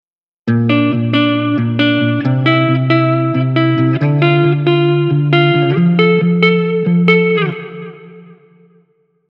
オリジナルのギターサンプルをカットし、順番を並び替えます。
このギターサンプルは、1小節ごとにコードチェンジしているので、各小節の頭でカットしています。
Guitar-Original.mp3